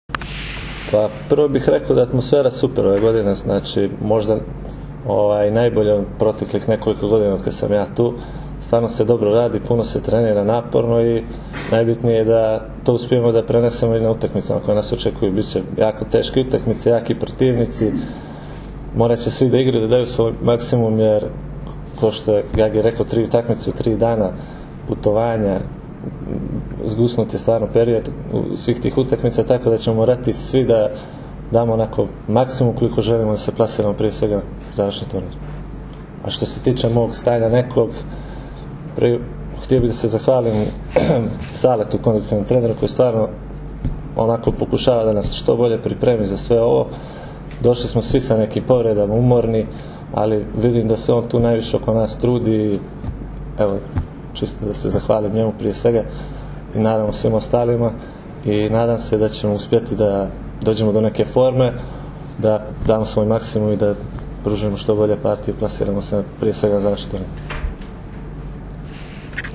Tim povodom, danas je u beogradskom hotelu “M” održana konferencija za novinare, na kojoj su se predstavnicima medija obratili Nikola Grbić, Dragan Stanković, Aleksandar Atanasijević i Miloš Nikić.
IZJAVA MILOŠA NIKIĆA